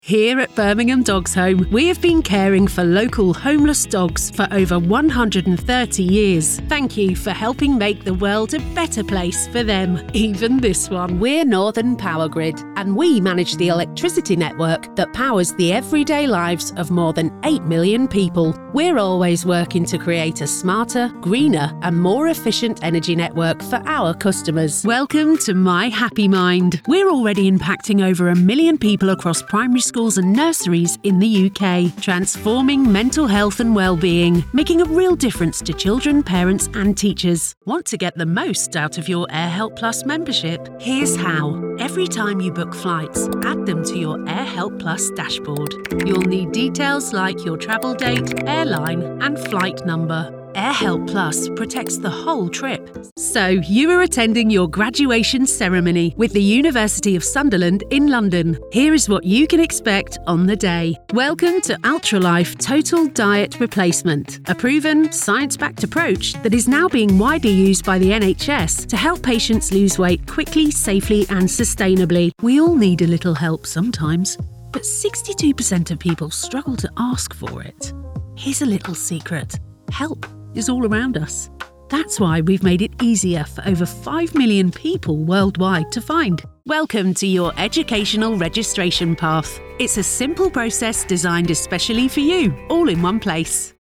From warm, smooth and classy to fresh, upbeat and natural.
Promo Demo
Middle Aged
I have my own professional home studio and can deliver a fast turnaround between 24-48 hours.